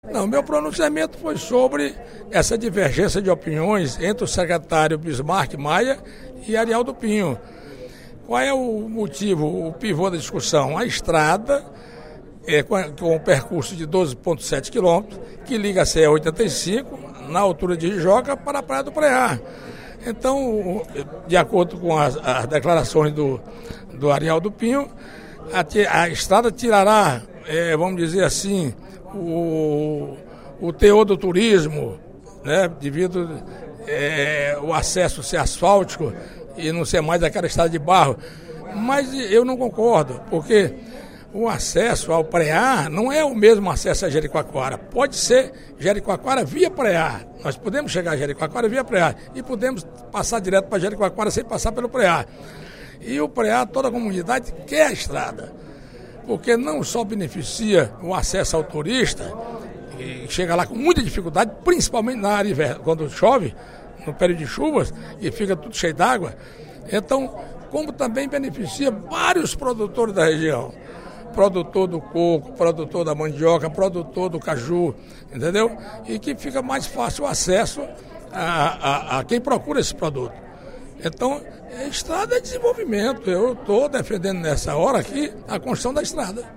No primeiro expediente da sessão plenária desta quinta-feira (24/10), o deputado Manoel Duca (Pros) defendeu a construção de estrada que ligue a CE-085 à comunidade do Preá, no município de Cruz, próxima ao Parque Nacional de Jericoacoara.